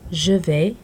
Click each word to hear the pronunciation.